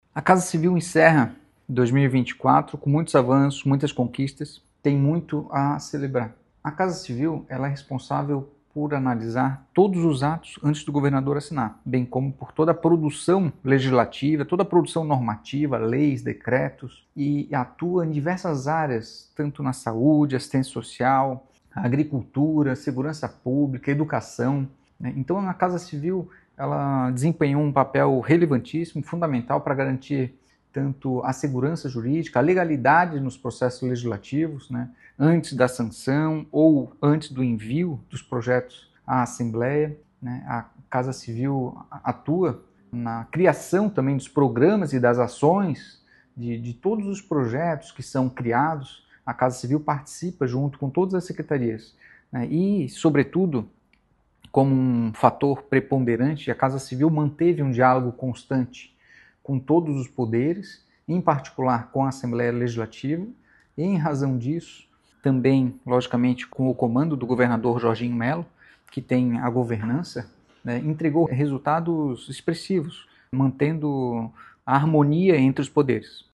O Secretário da pasta, Marcelo Mendes, avalia 2024 como um ano de avanços e conquistas: